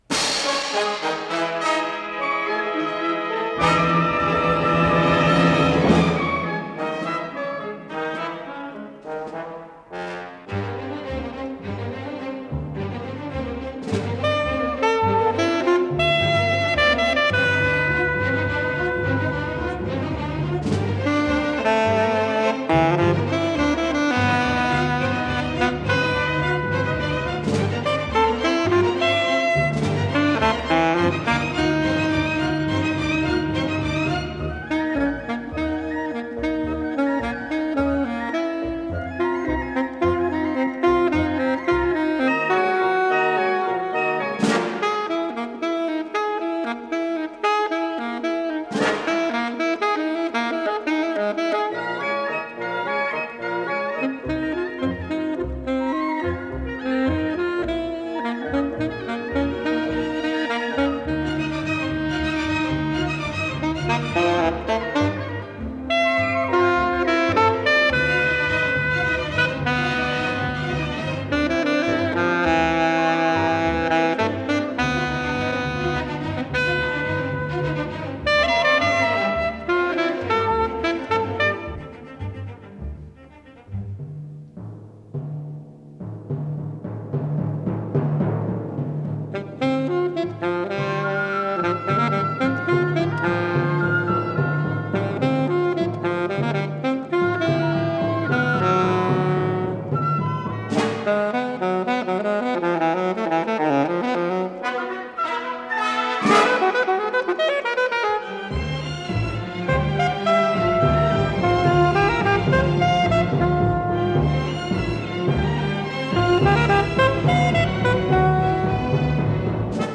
До мажор